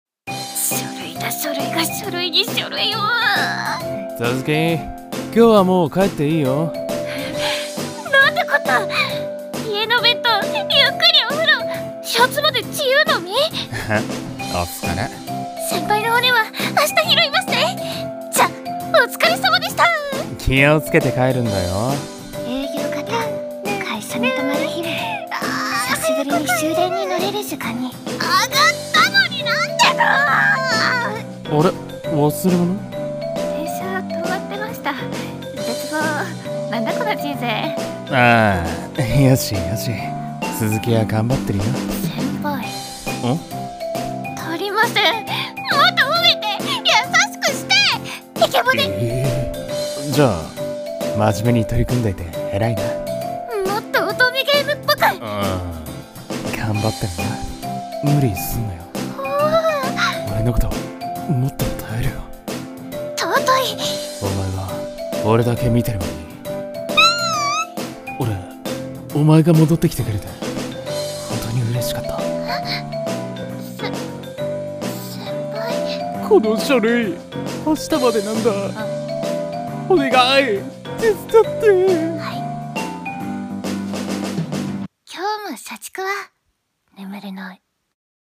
【二人声劇】今日も社畜は眠れない